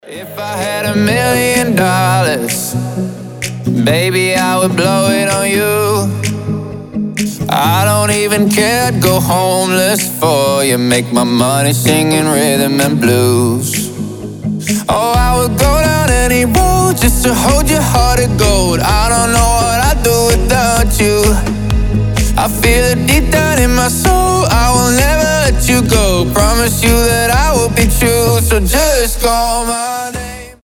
красивый мужской голос
щелчки